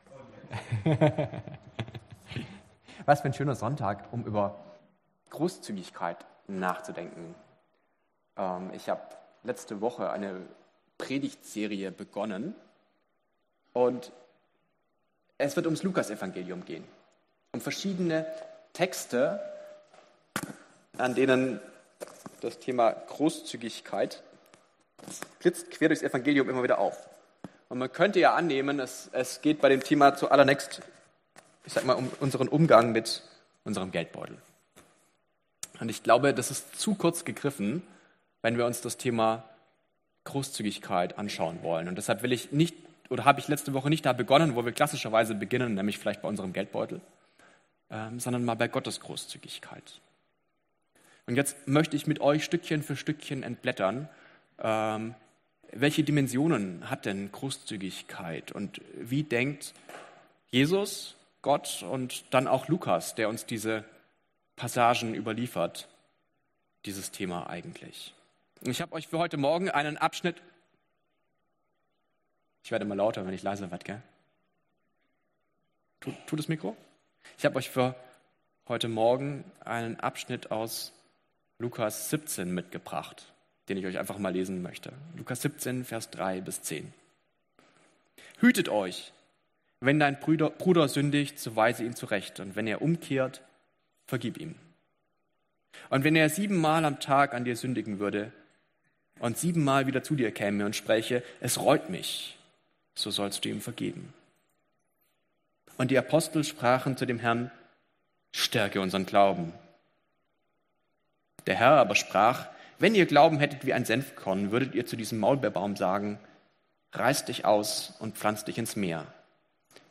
3-10 Dienstart: Gottesdienst „Der Geist ist willig